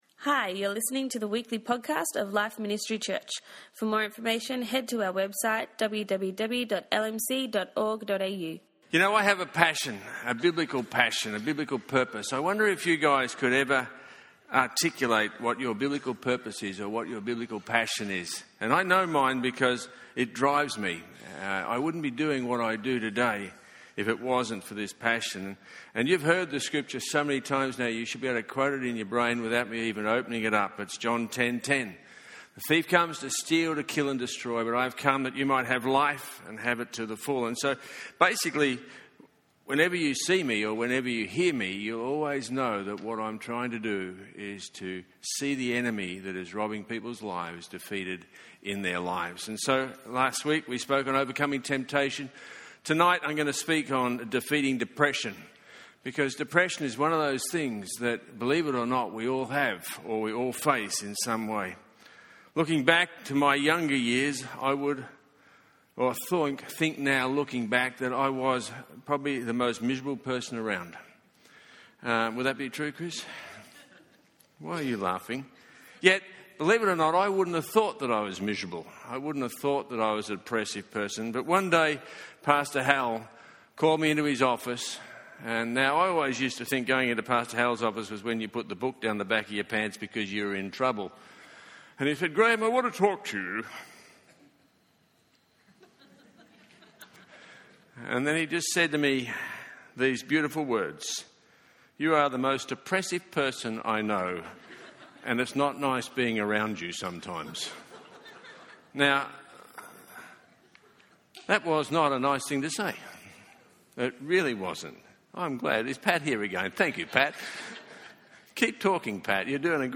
download the notes In this practical message